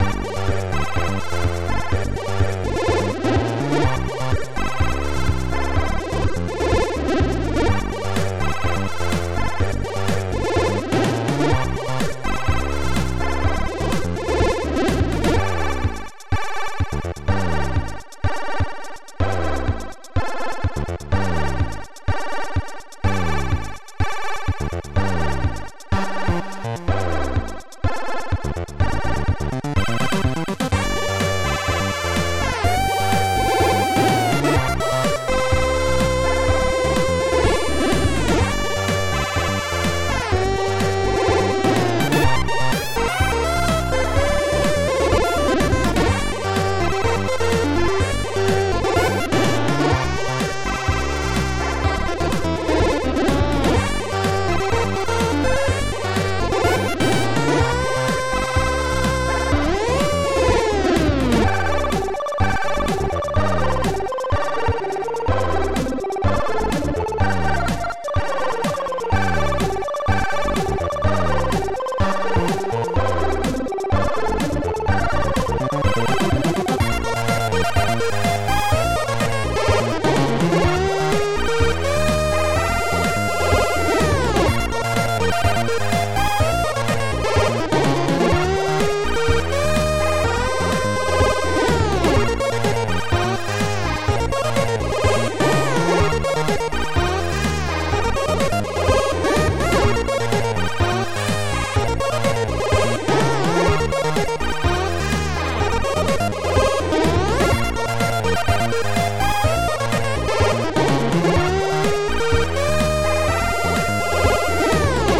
Protracker Module
2 channels